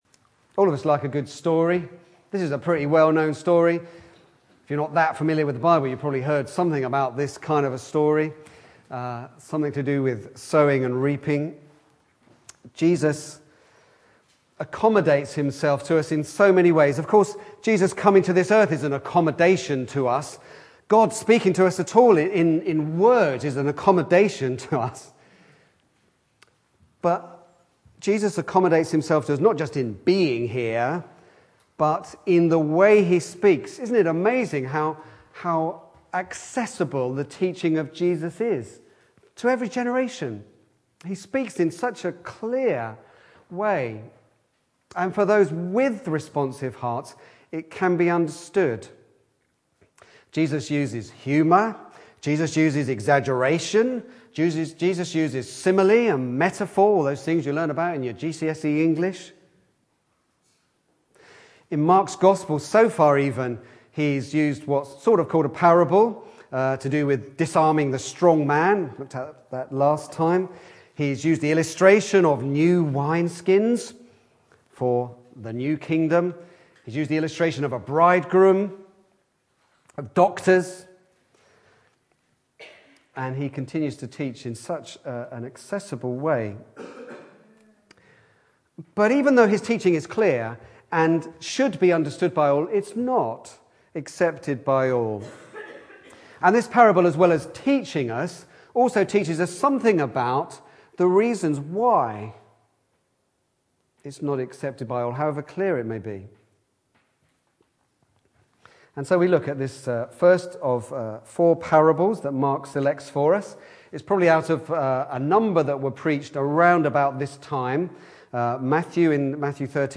Back to Sermons Sowing and Reaping